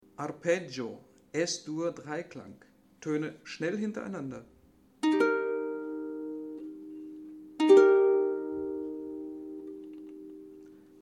Auf der Harfe wird jeder Ton des Arpeggios mit einem eigenen Finger gezupft!
Hörbeispiel Arpeggio